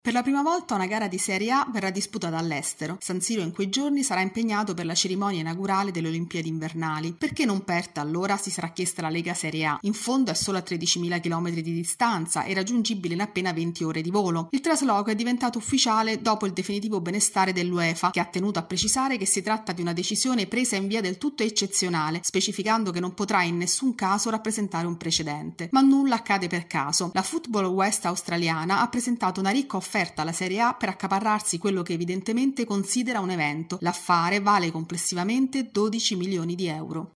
La partita tra Milan e Como dell’8 febbraio si giocherà in Australia. Il servizio